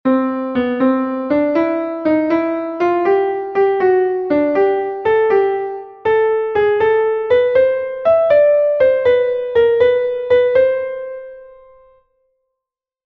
Exercise 3: 6/8 time signature.